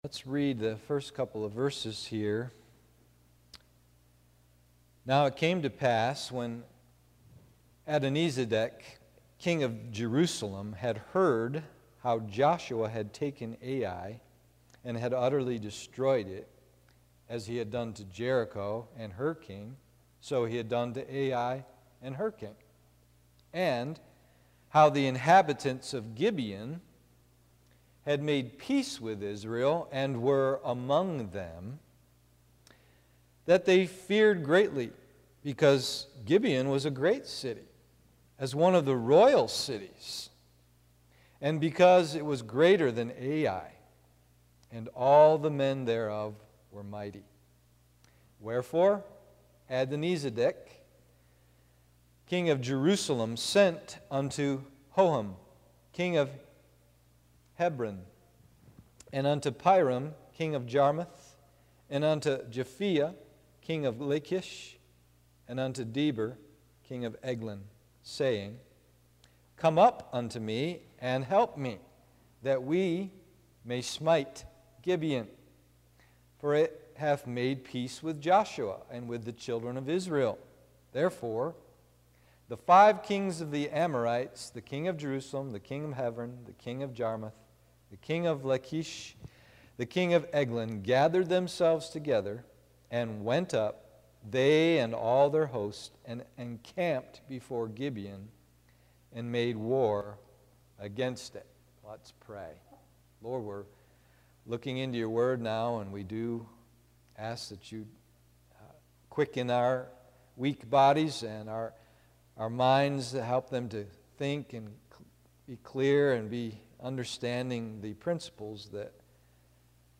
Joshua 10 and Pilgrim’s Progress Study pp. 52-58 PM Service